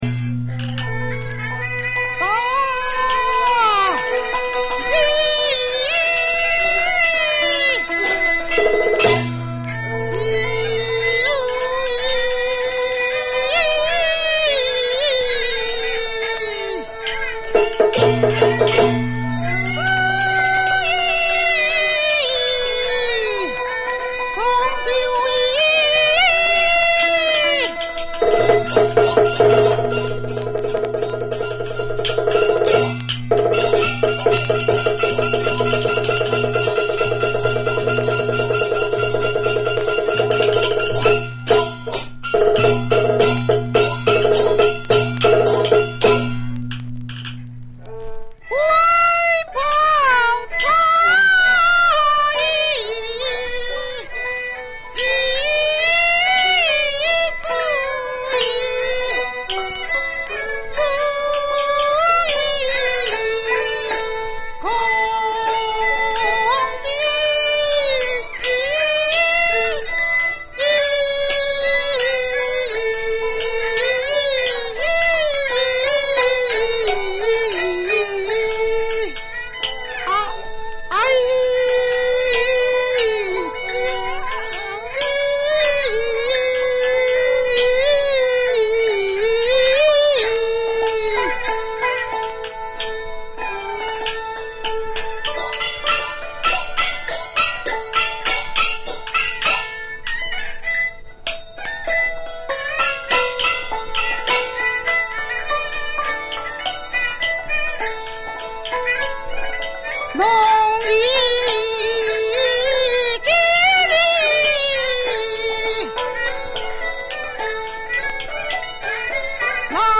三進宮【倒板】、【二黃】 北管新路戲曲 梨春園早期錄音資料計畫